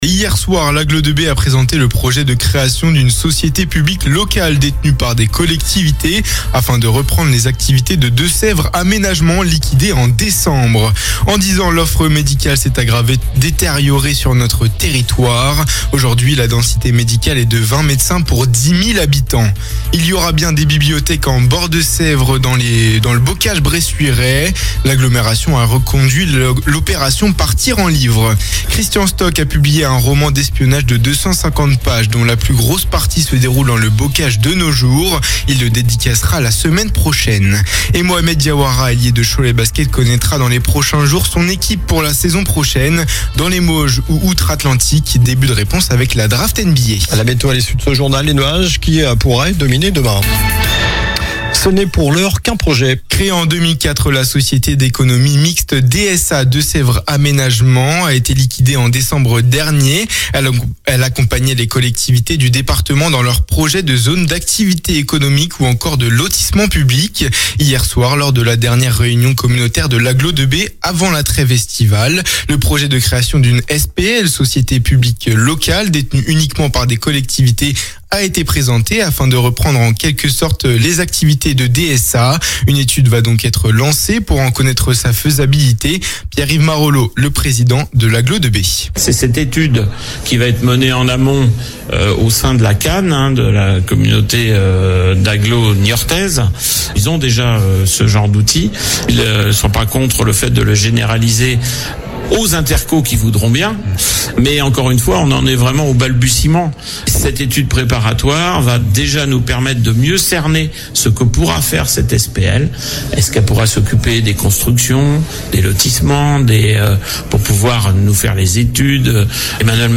Journal du mercredi 25 juin (soir)